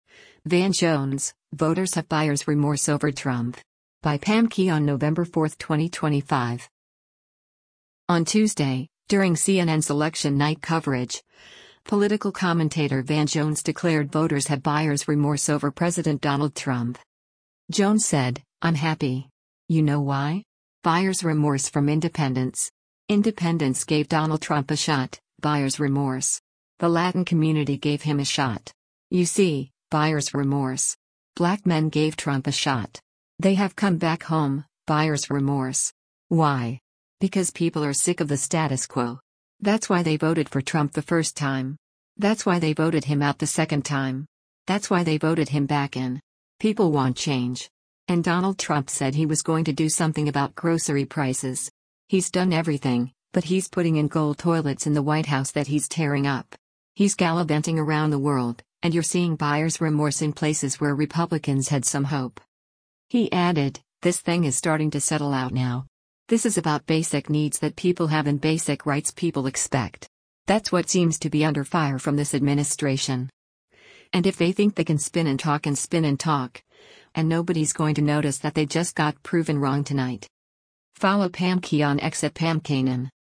On Tuesday, during CNN’s election night coverage, political commentator Van Jones declared voters have “buyers remorse” over President Donald Trump.